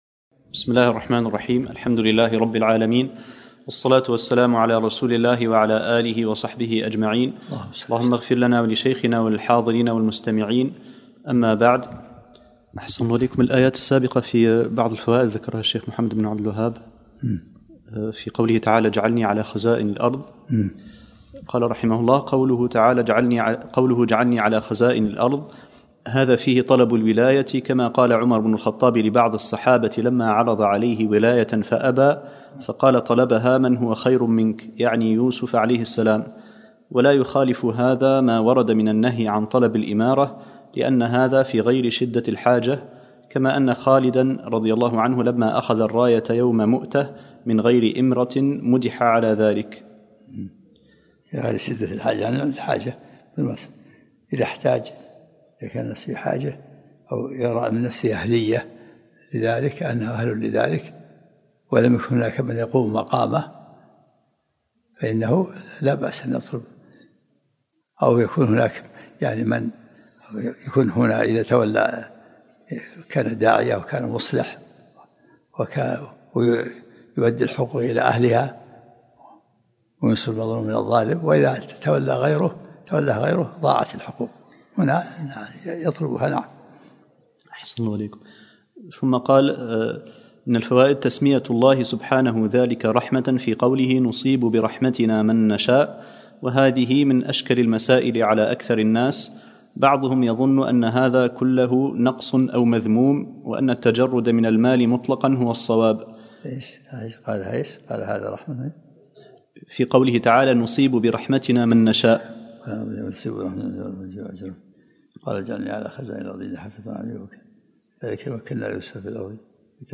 الدرس الحادي عشرمن سورة يوسف